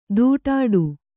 ♪ dūṭāḍu